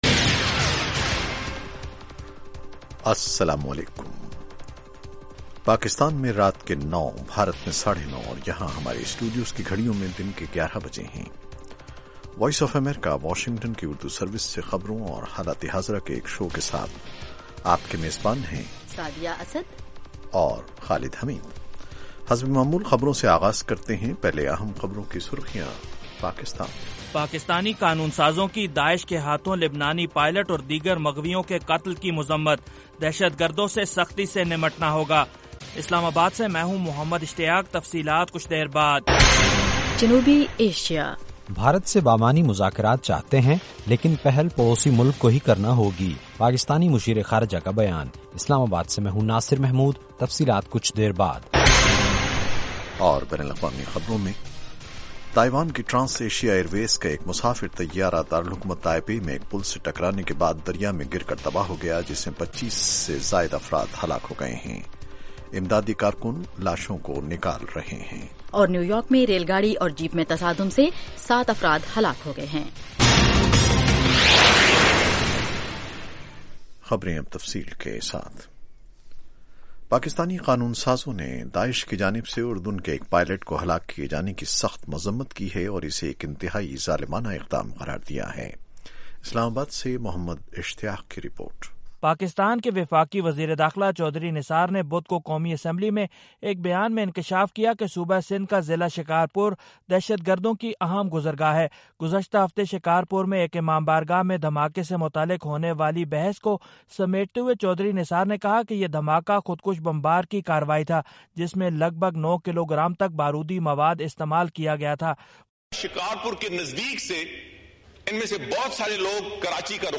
اس کے علاوہ انٹرویو، صحت، ادب و فن، کھیل، سائنس اور ٹیکنالوجی اور دوسرے موضوعات کا احاطہ۔